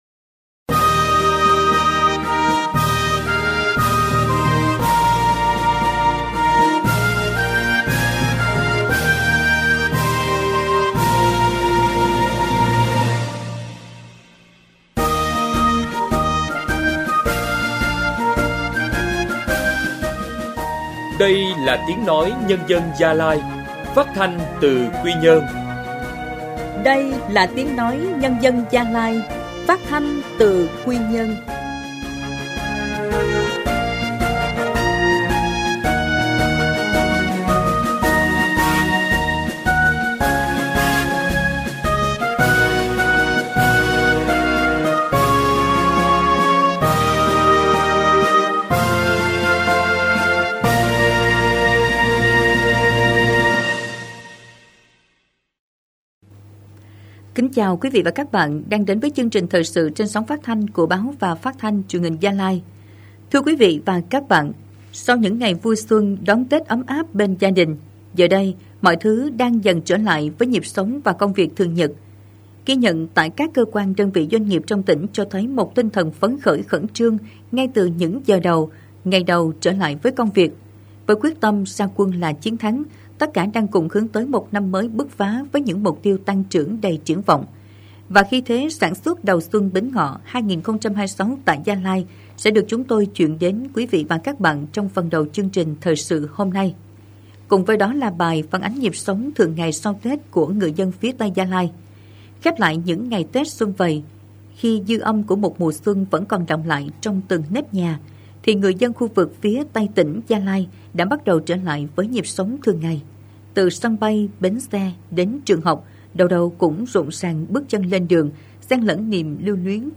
Thời sự phát thanh trưa